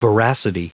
Prononciation du mot voracity en anglais (fichier audio)
Prononciation du mot : voracity